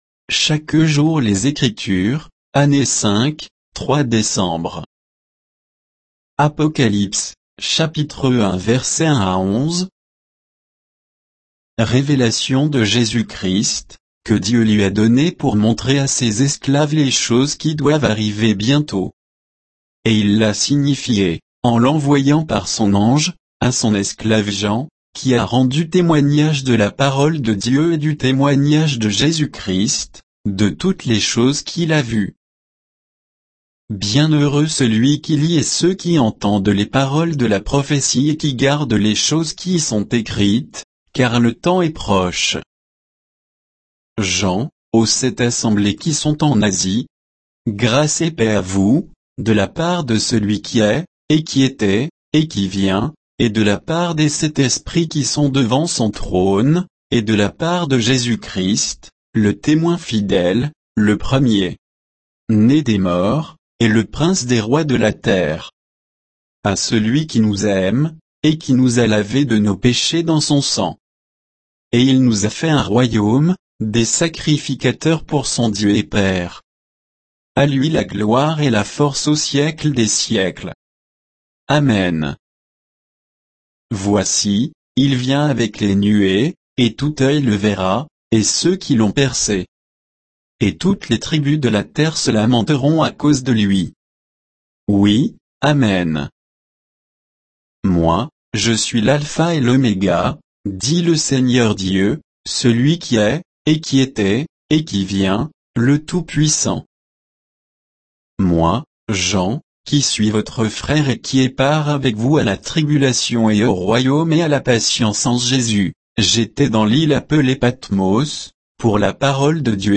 Méditation quoditienne de Chaque jour les Écritures sur Apocalypse 1